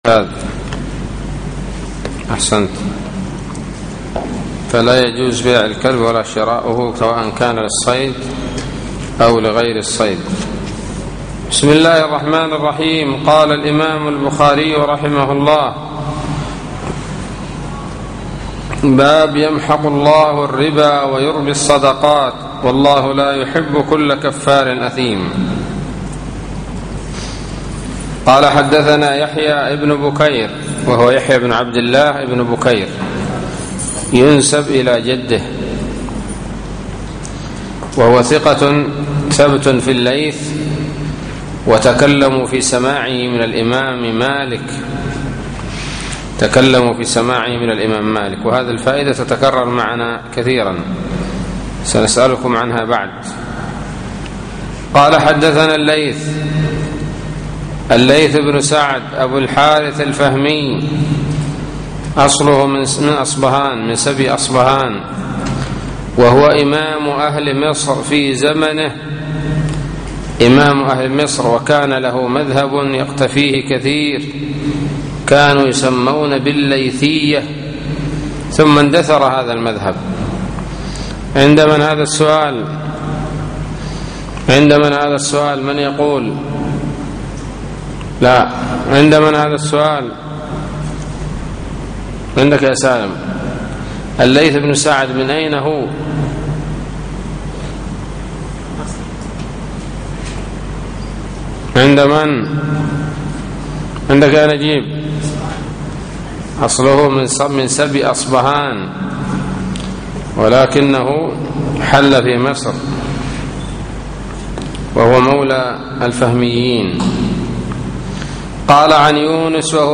الدرس الثالث والعشرون من كتاب البيوع من صحيح الإمام البخاري